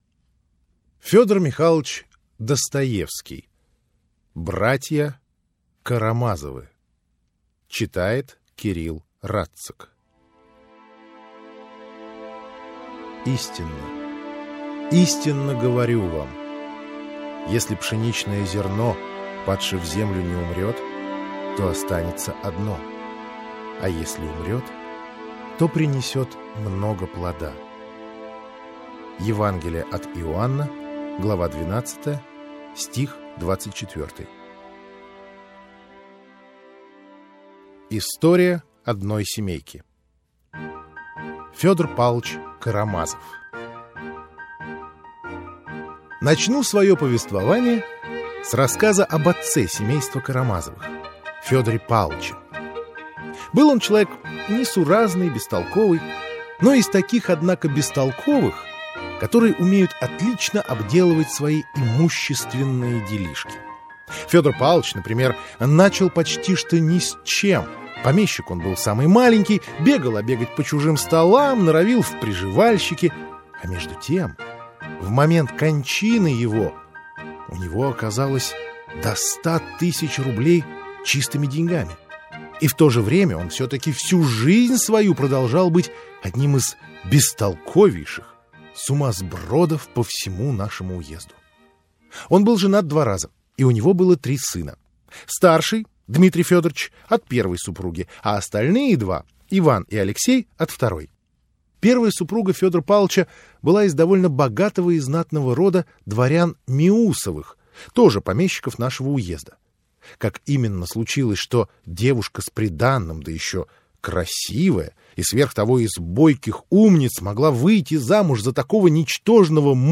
Аудиокнига Братья Карамазовы (сокращенный пересказ) | Библиотека аудиокниг
Aудиокнига Братья Карамазовы (сокращенный пересказ) Автор Федор Достоевский Читает аудиокнигу Кирилл Радциг.